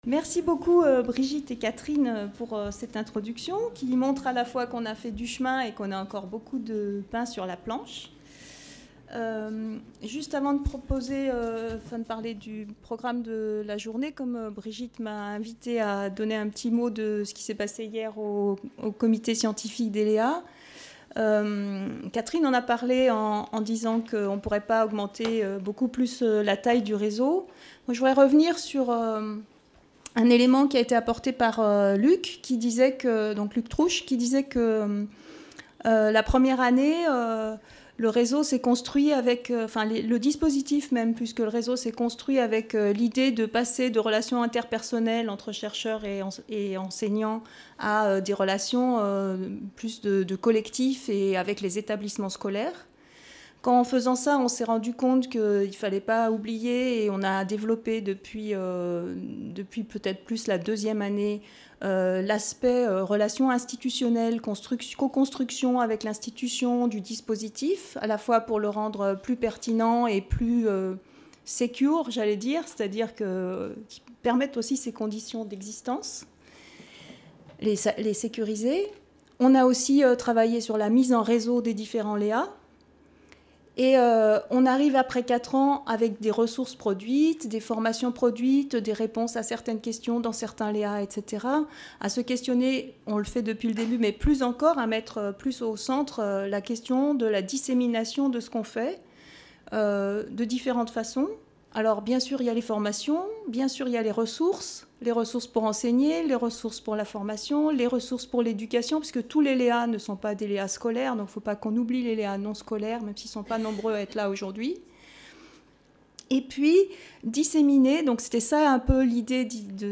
Comment articuler recherche et production de ressources ? La 5° rencontre nationale des LéA qui s'est tenue à l'IFÉ le 13 mai 2015, a interrogé l'articulation entre recherche et production de ressources pour l'enseignement, la formation et l'éducation, à partir des ressources produites depuis 4 ans par les LéA.